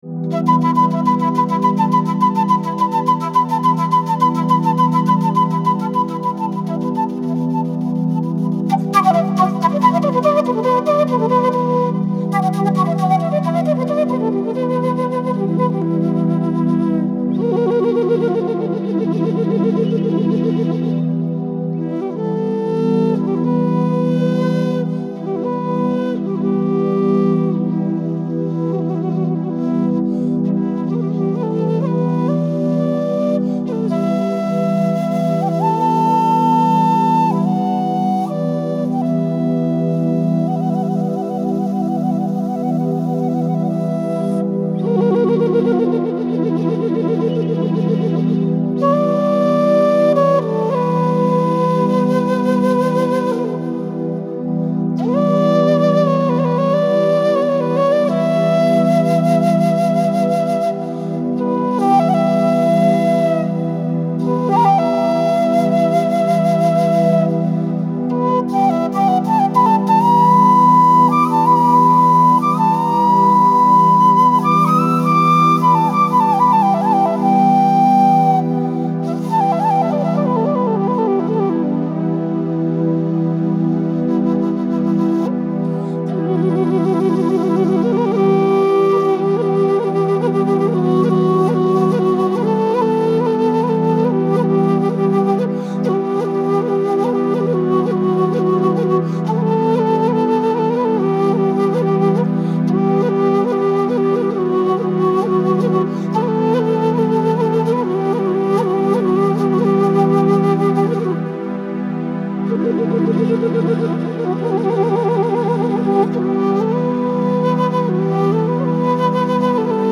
Analogue Disco
Bass House Synths
Dreamy Pop
Drones & Backgrounds
Hip Hop Vocals
Total Metal Guitars